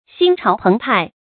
注音：ㄒㄧㄣ ㄔㄠˊ ㄆㄥˊ ㄆㄞˋ
心潮澎湃的讀法